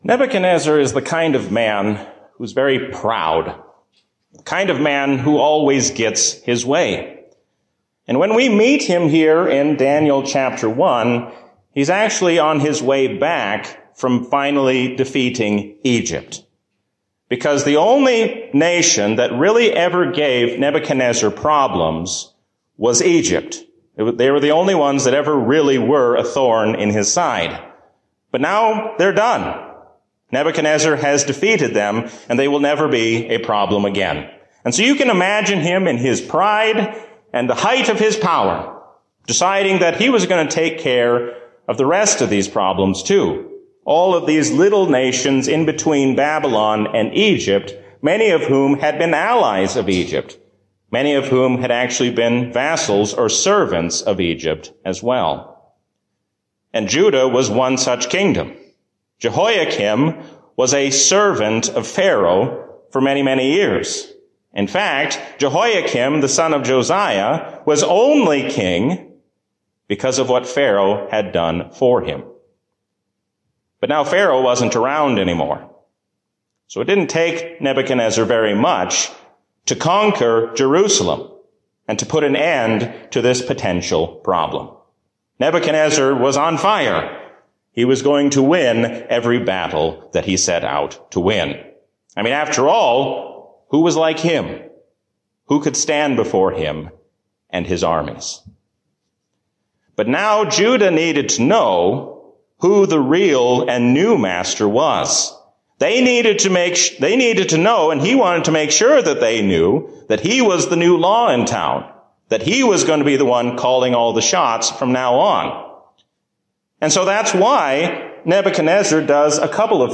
A sermon from the season "Easter 2024." Do not give in to the allure of this world, but stand fast, knowing that God will soon bring all evil to an end.